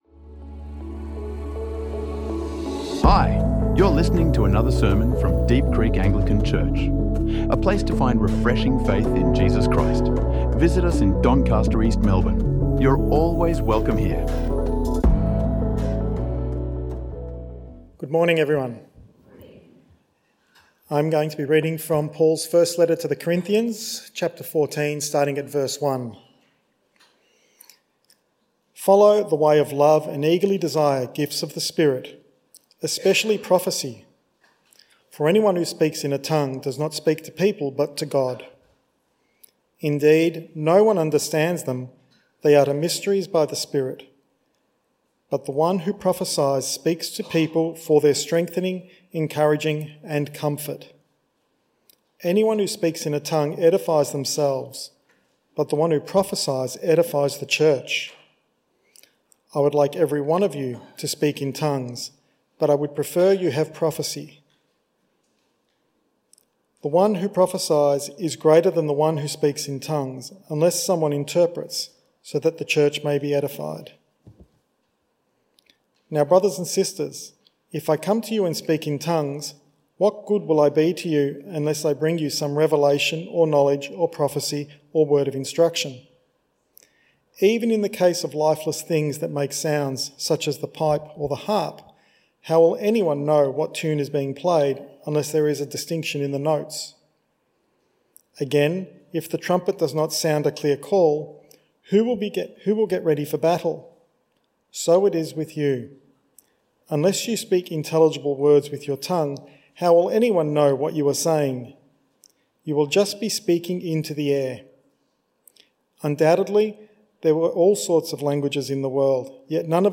In this sermon, we explore 1 Corinthians 14, where Paul calls the church to edify one another, worship with understanding, and...